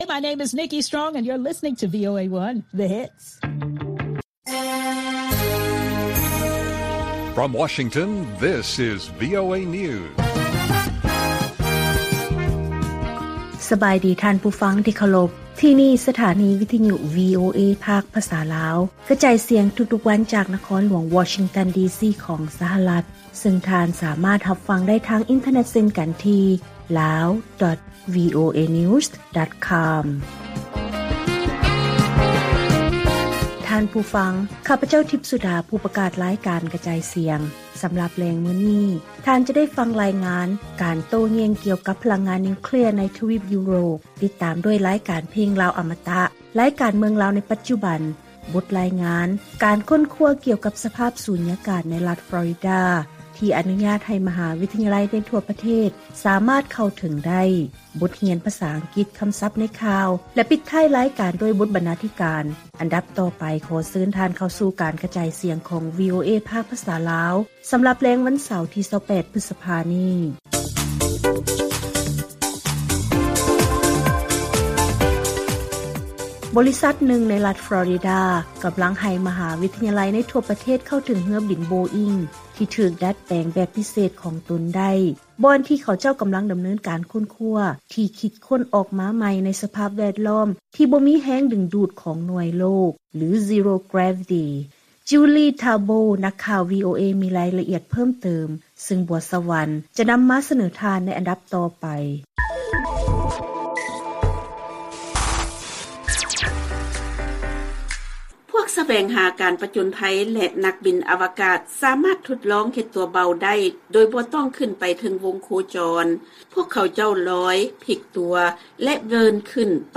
ລາຍການກະຈາຍສຽງຂອງວີໂອເອລາວ: ສົງຄາມໃນຢູເຄຣນ ແລະເປົ້າໝາຍດ້ານສະພາບອາກາດ ຊຸກຍູ້ໃຫ້ຢູໂຣບບາງສ່ວນ ພິຈາລະນາຄືນ ພະລັງງານນິວເຄລຍ